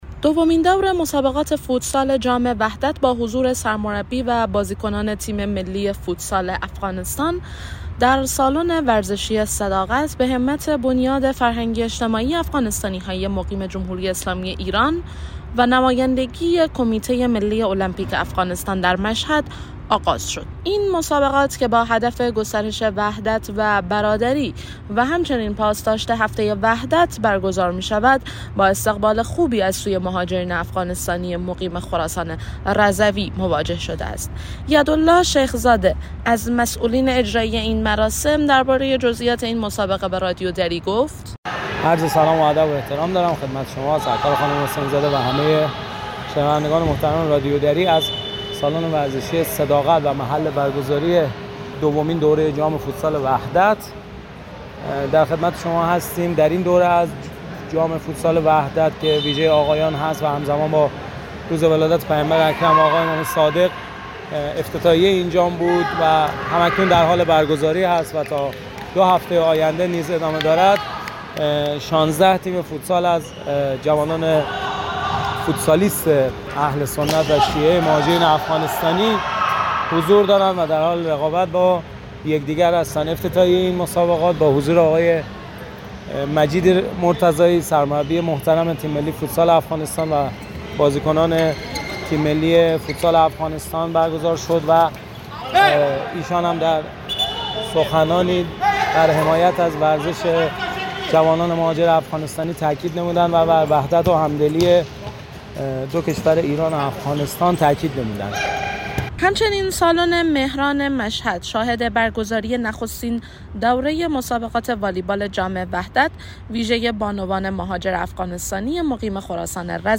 خبر / ورزشی